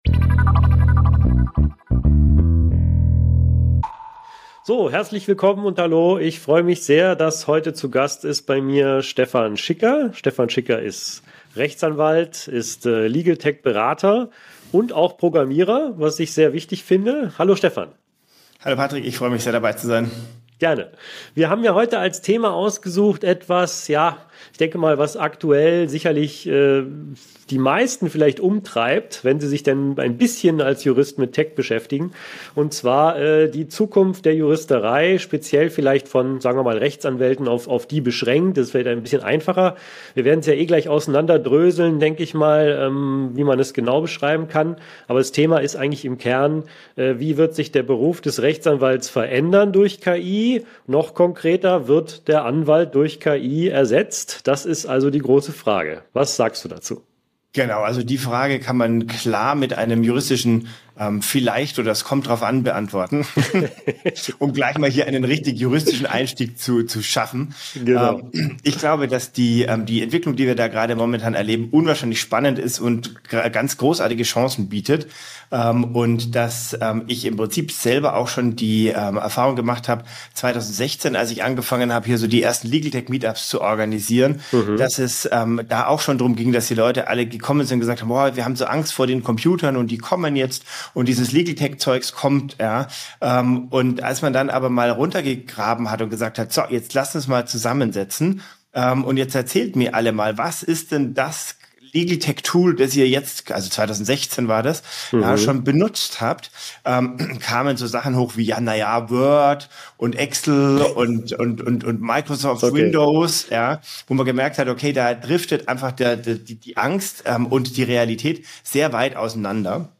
interviewt